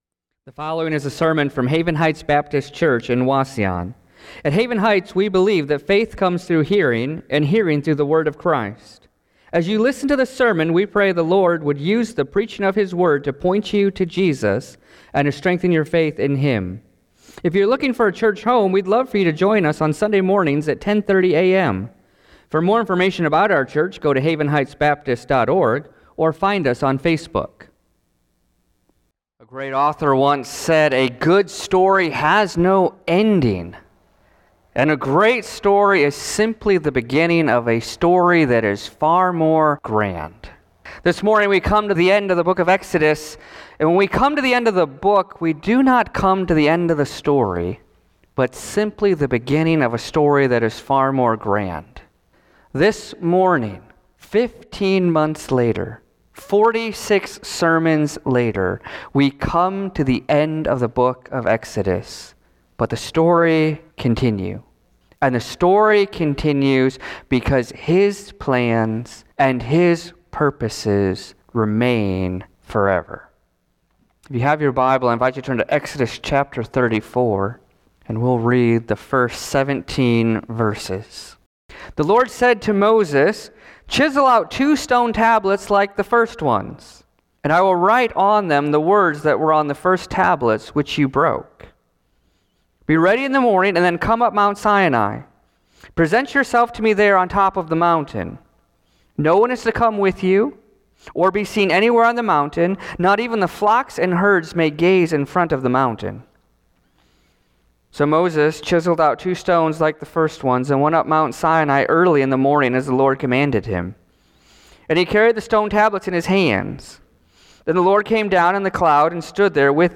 Sermons | Haven Heights Baptist Church